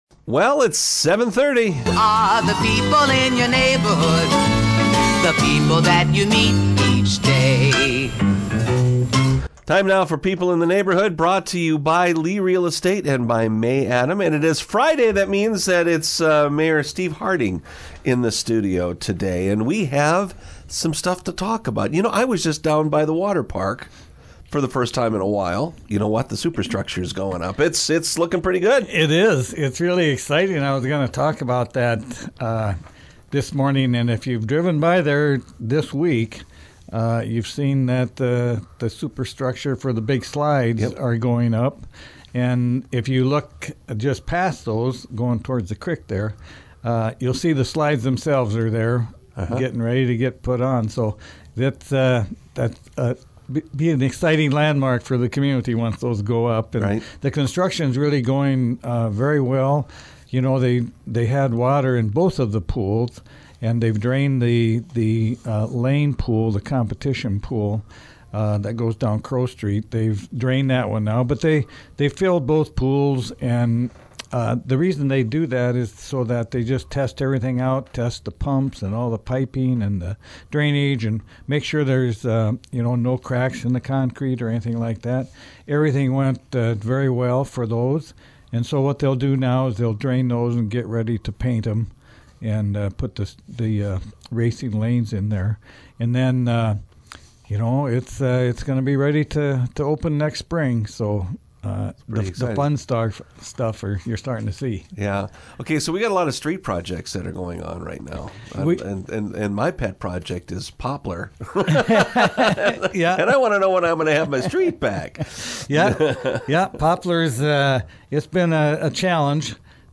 getting an update from Pierre Mayor Steve Harding